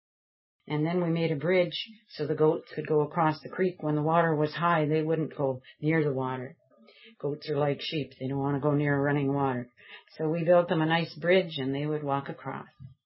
Goats - Sound Clip